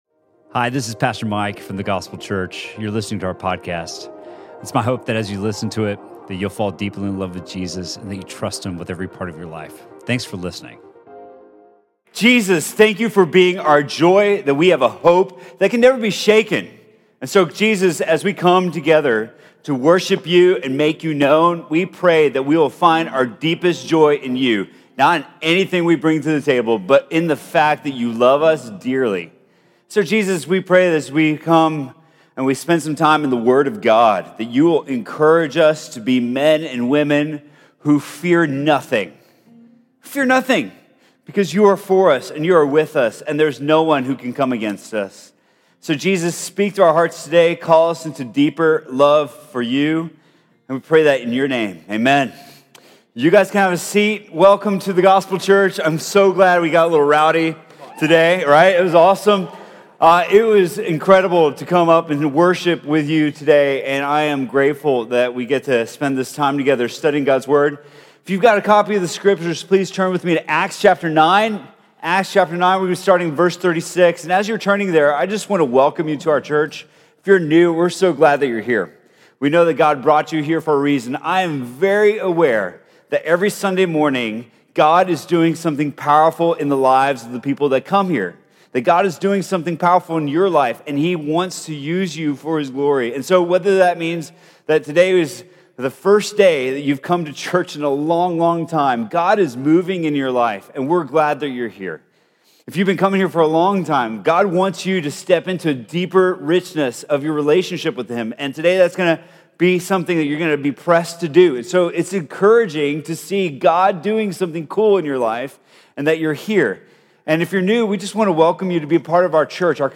Sermon from The Gospel Church on November 18th, 2018.